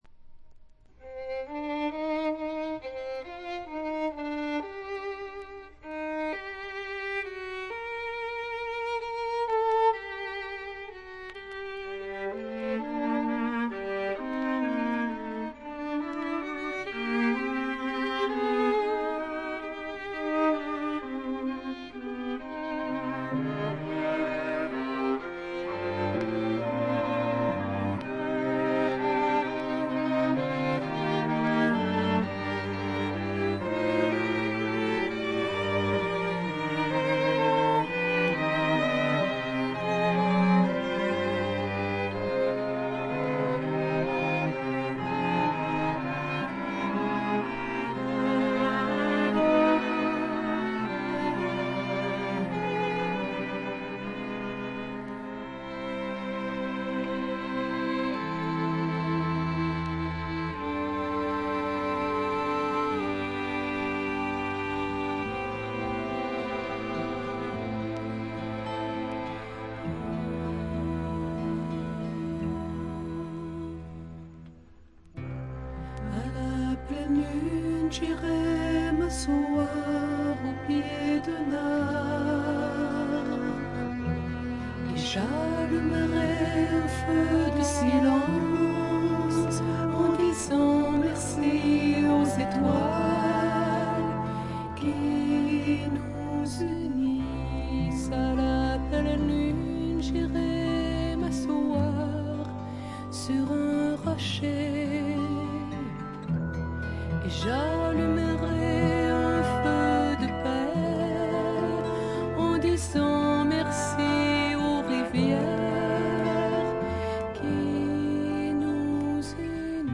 静音部で軽微なバックグラウンドノイズやチリプチが少々認められる程度。
カナディアン・プログレッシヴ・ドリーミー・フォークの名作。
試聴曲は現品からの取り込み音源です。